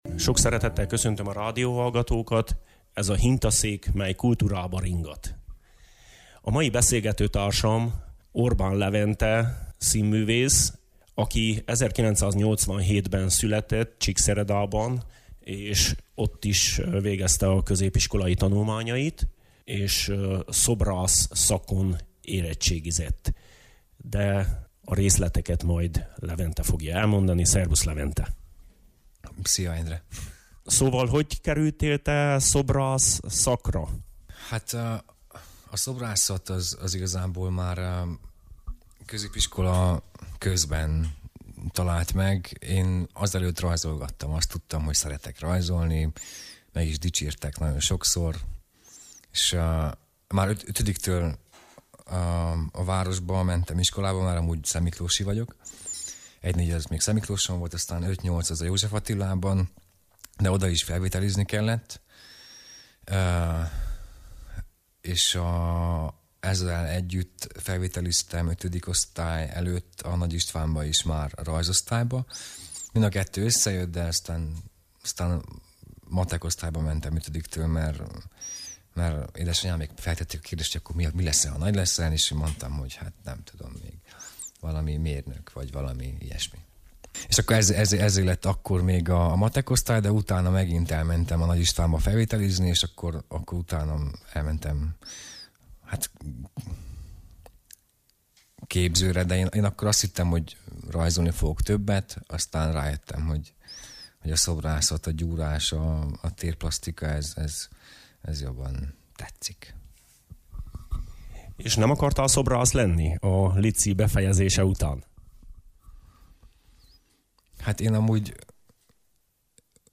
vele beszélget egy jót